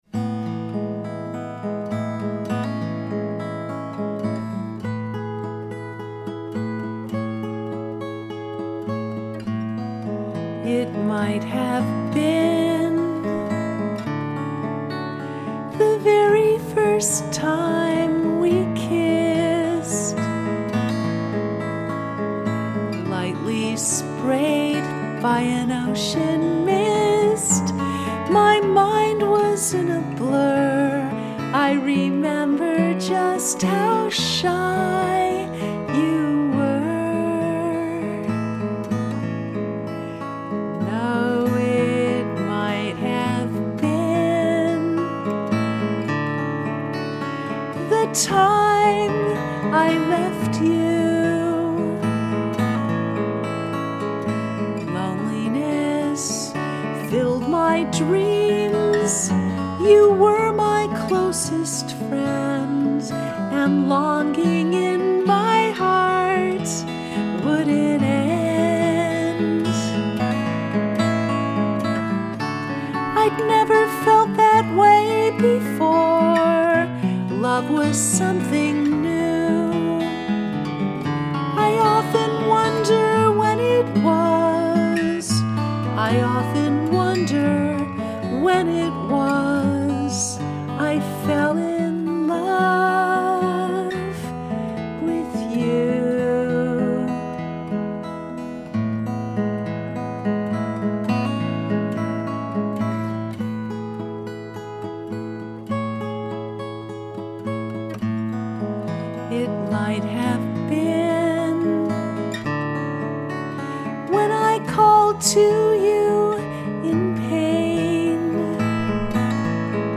It Might Have Been Piano Guitar